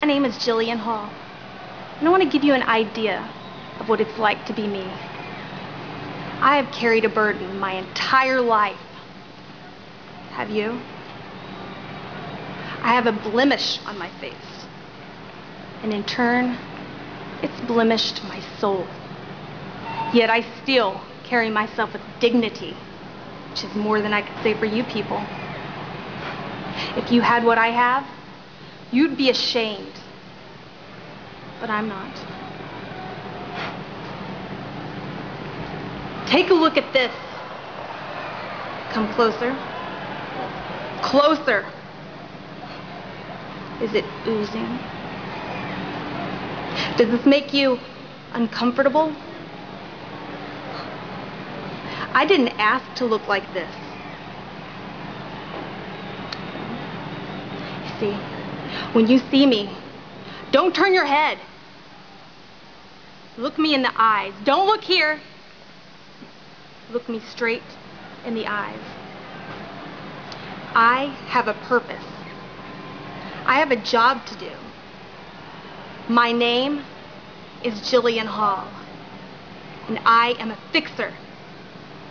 a passionate speech about her “blemish” had “blemished her soul.”